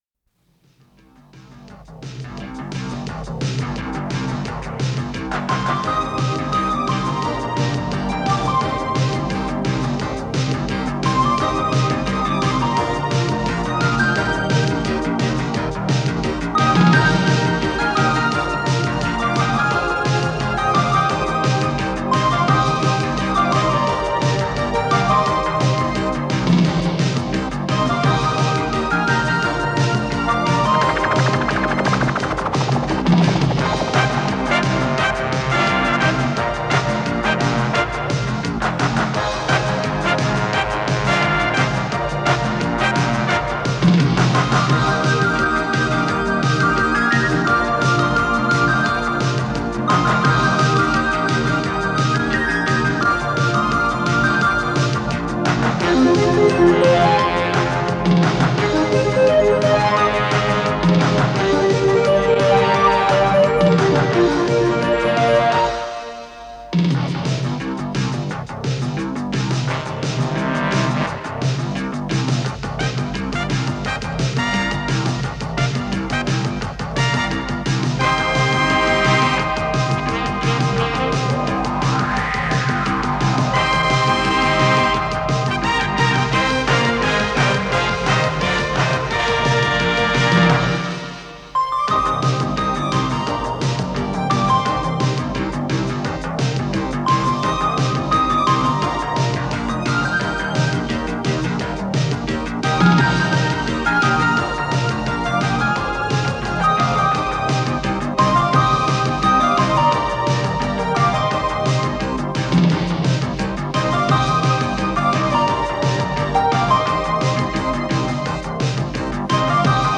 с профессиональной магнитной ленты
Скорость ленты38 см/с
Тип лентыORWO Typ 106